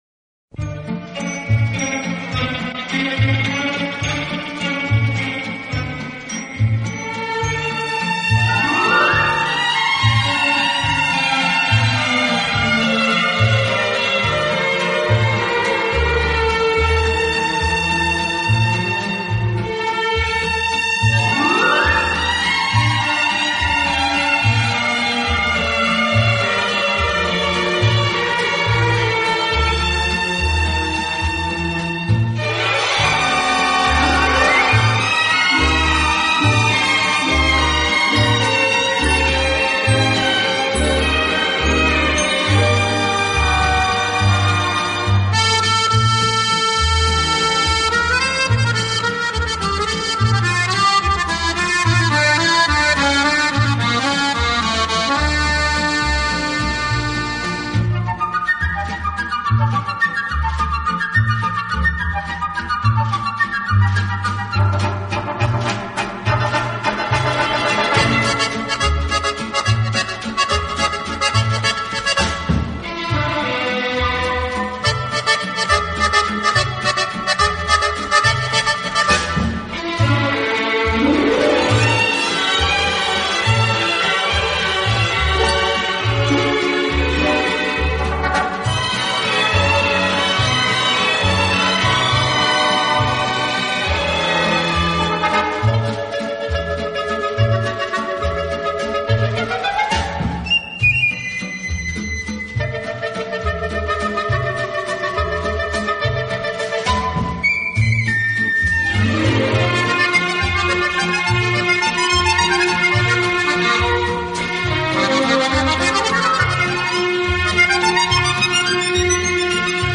Genre: Easy Listening